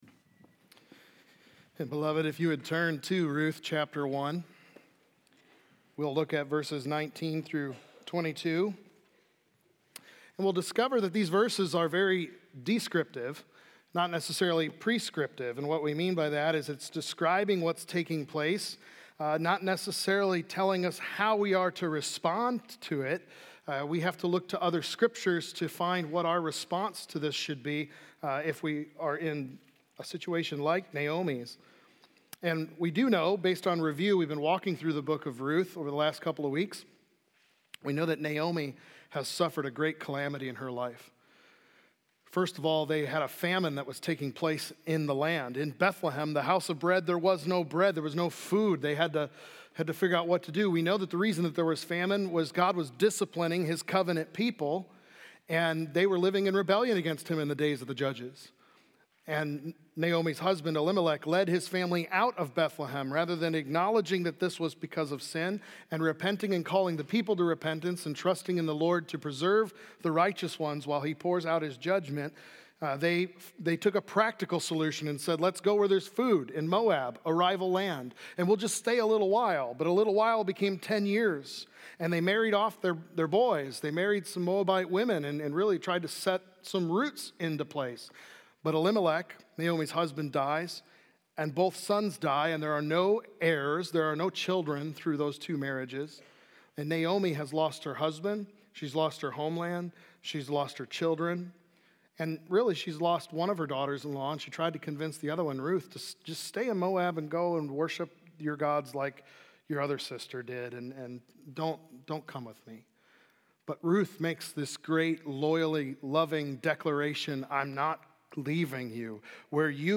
| Baptist Church in Jamestown, Ohio, dedicated to a spirit of unity, prayer, and spiritual growth